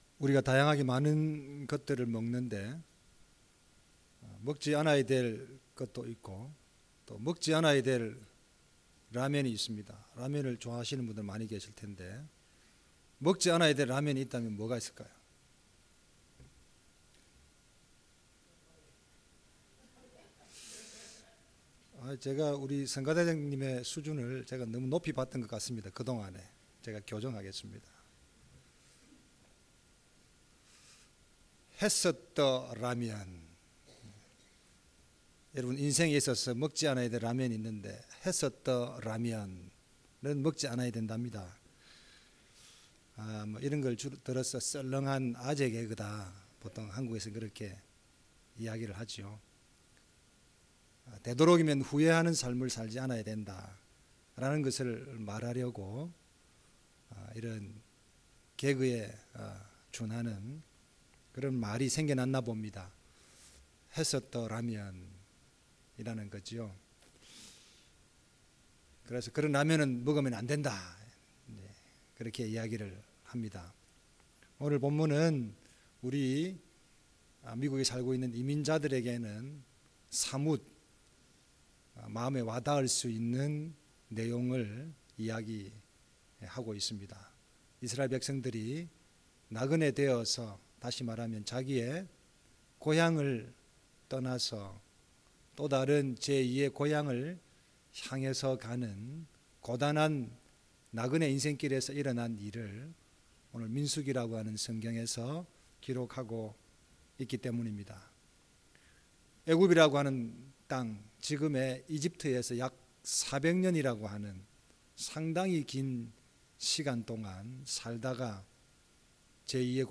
주일 설교